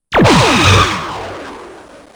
plasma.wav